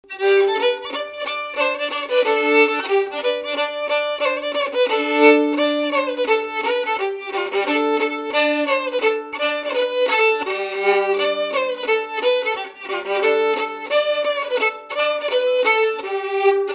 Thème : 1074 - Chants brefs - A danser
danse : scottish (autres)
Enquête Arexcpo en Vendée
Catégorie Pièce musicale inédite